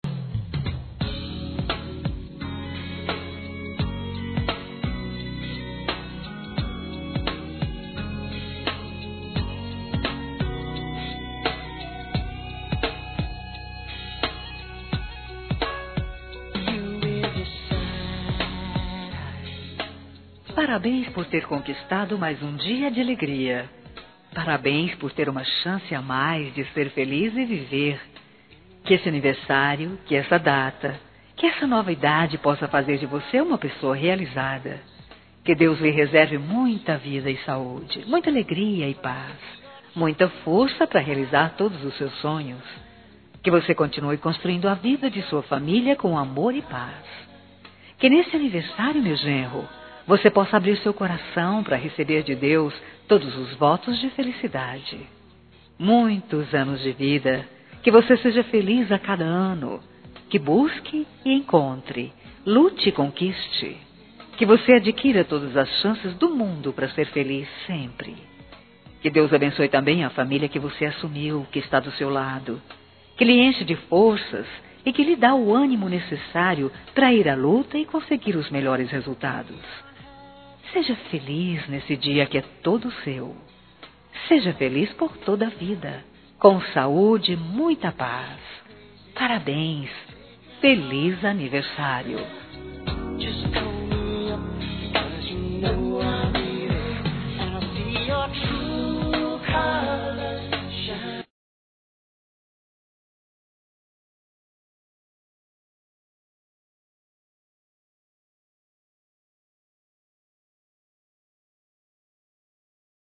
Aniversário de Genro – Voz Feminina – Cód: 2430